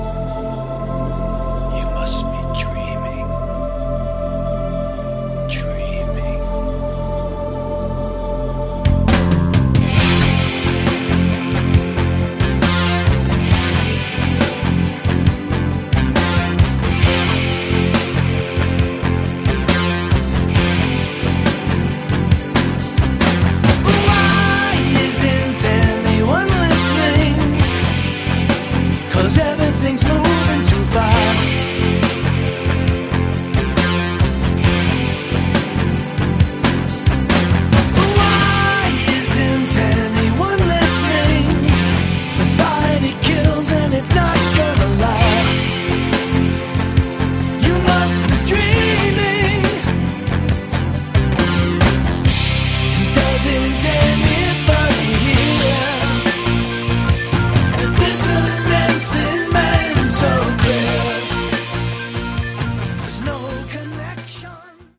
Category: Progish AOR